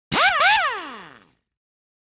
Impact Wrench
yt_OyN6EwixjlM_impact_wrench.mp3